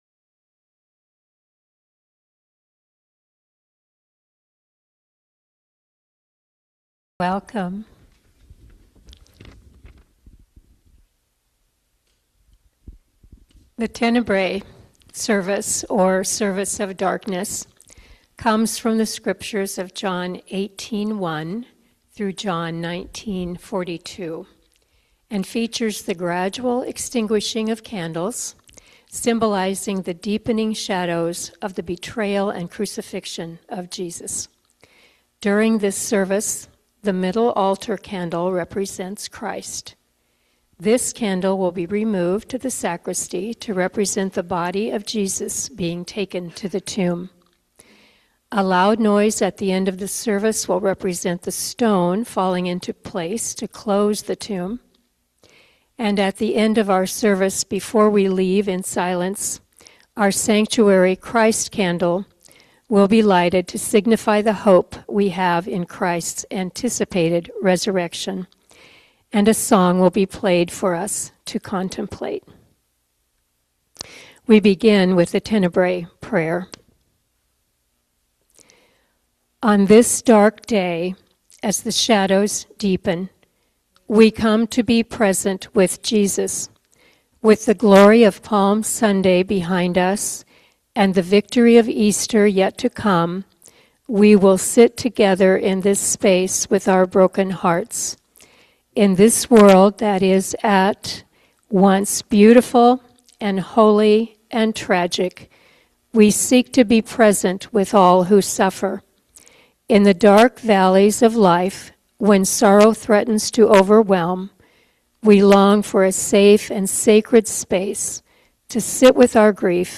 Good Friday Tenebrae Service